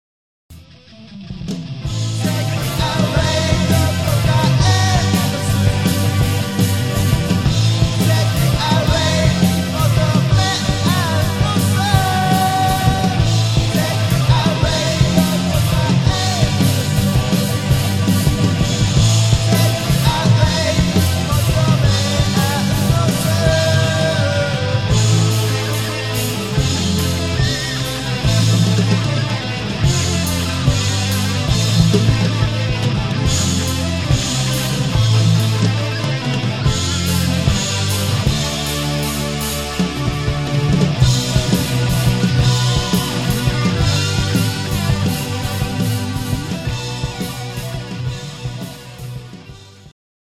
ミディアムテンポでメロディアスなサウンドを中心とした正統派ハードロックバンドである。